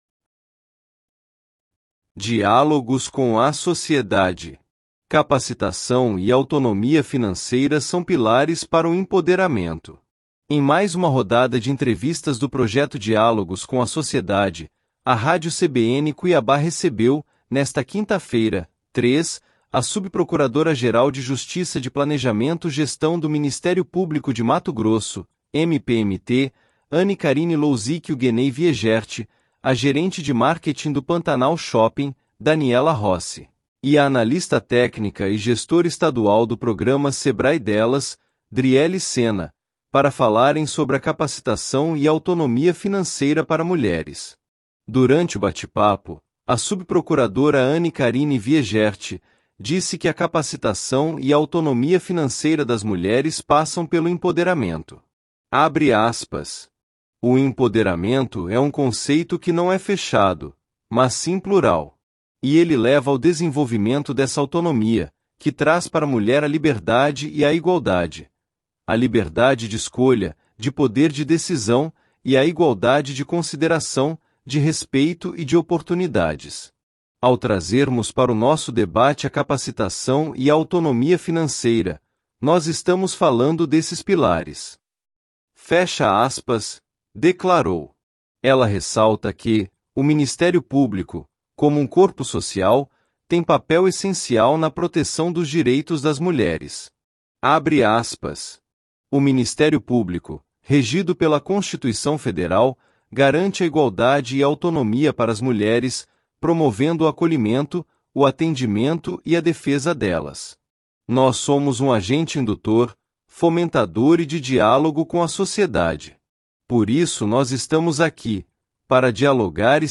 As entrevistas do projeto Diálogos com a Sociedade seguem até o dia 11 de abril, das 14h às 15h, no estúdio de vidro localizado na entrada principal do Pantanal Shopping, com transmissão ao vivo pelo canal do MPMT no YouTube.